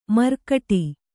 ♪ markaṭi